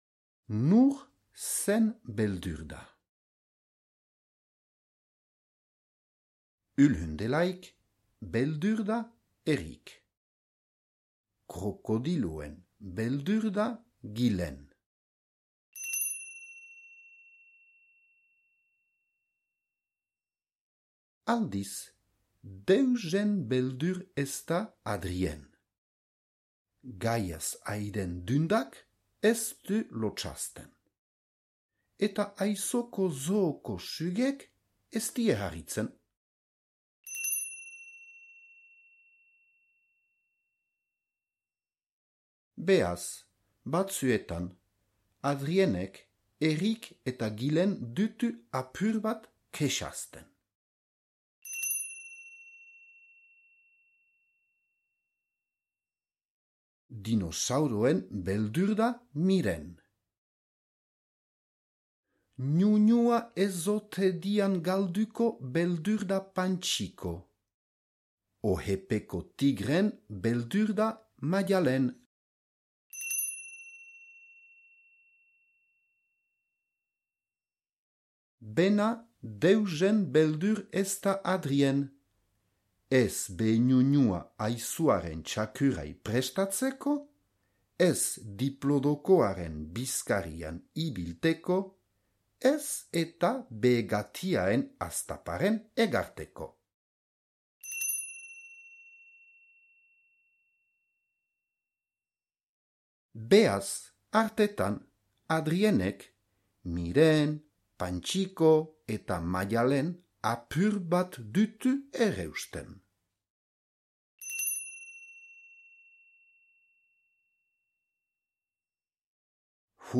Nor zeren beldür da? - zubereraz - ipuina entzungai